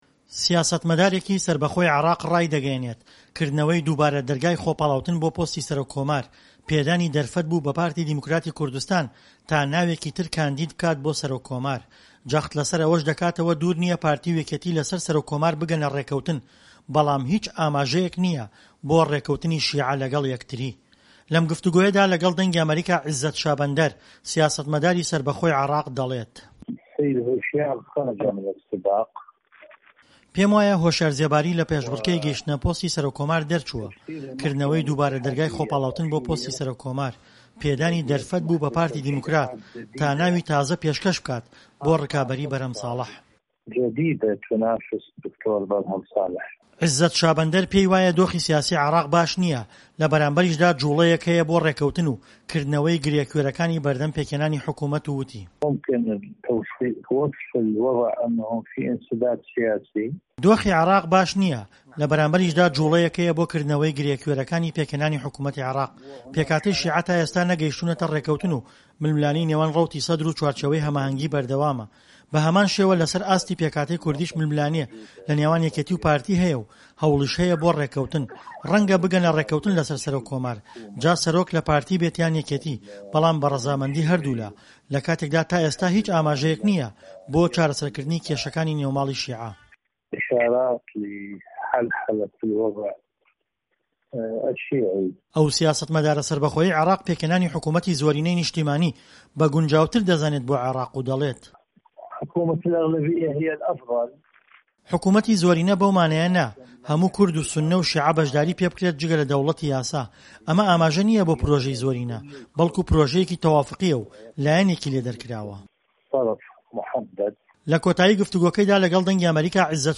لەم گفتووگۆیەدا لەگەڵ دەنگی ئەمەریکا، عیزەت شابەندەر سیاسەتمەداری سەربەخۆی عێراق دەڵێت"پێموایە هۆشیار زێباری لە پێشبڕکێی گەیشتنە پۆستی سەرۆک کۆمار دەرچووە، کردنەوەی دووبارە دەرگای خۆپاڵاوتن بۆ پۆستی سەرۆک کۆمار، پێدانی دەرفەتبوو بە پارتی دیموکرات، تا ناوی تازە پێشکەش بکات بۆ ڕکابەری بەرهەم ساڵەح."